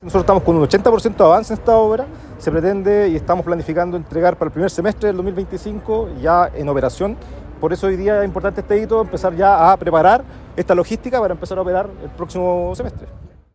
El seremi (s) de Obras Púbicas del Bío Bío, Claudio Morales, informó que las obras tienen un 80% de avance y se proyecta que esté operativo el primer semestre de 2025.